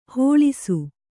♪ hōḷisu